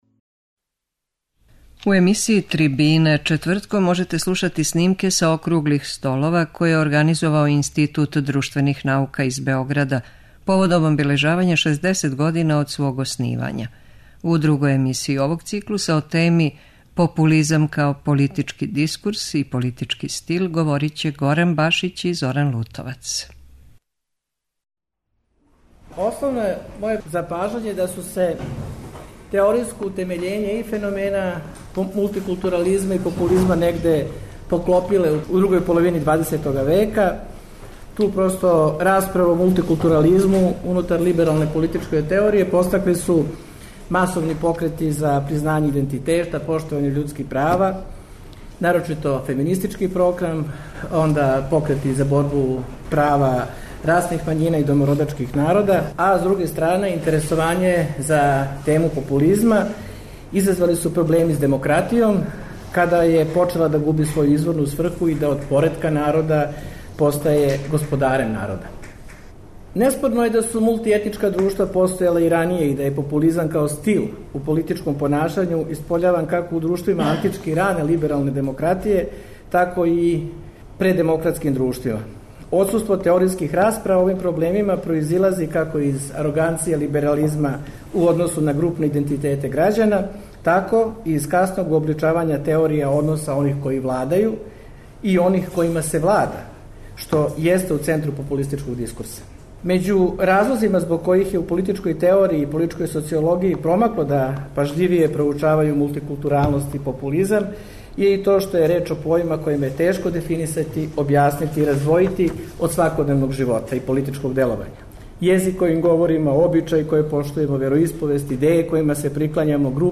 У емисији ТРИБИНЕ четвртком можете слушати снимке са округлих столова које током ове јесени организује Институт друштвених наука из Београда поводом обележавања 60 година од свог оснивања.